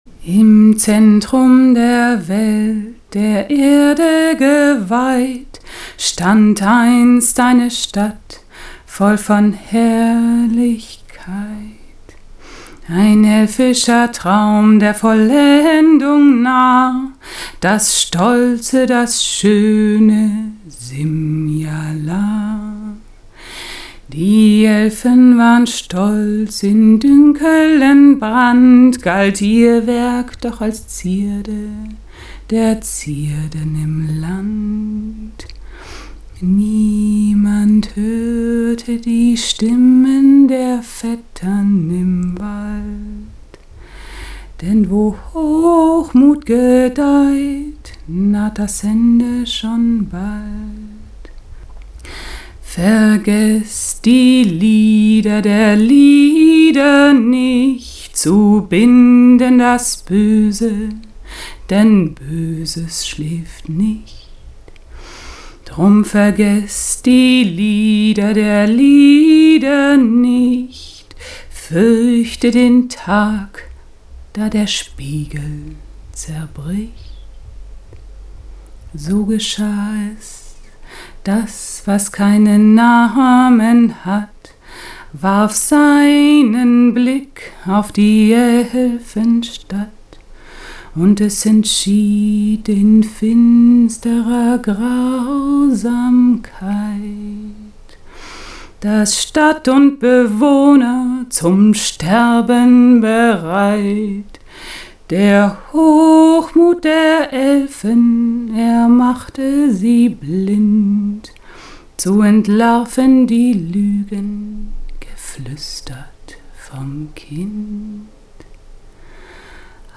Da laut Abenteuer der (männliche) Gaukler Arrigo derjenige ist, der das Lied vorträgt, sollte man ihn zu einer Sängerin machen - zum Beispiel als "Arriga".